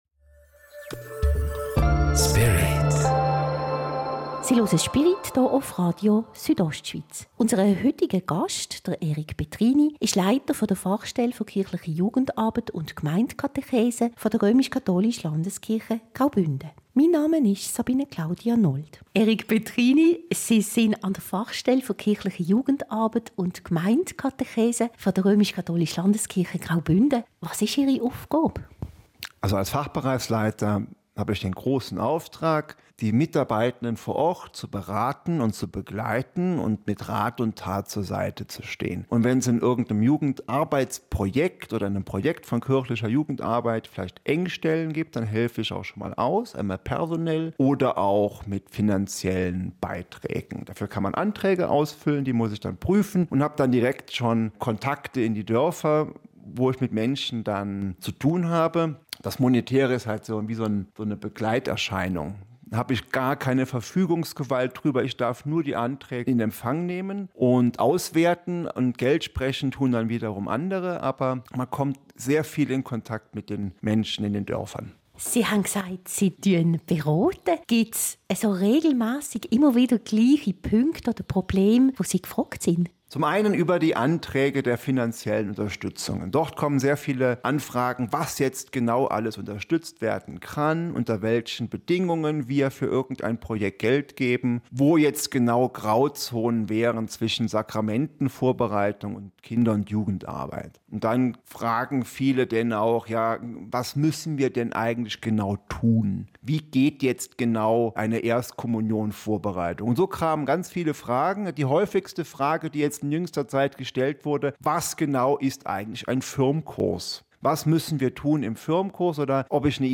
Radio Südostschweiz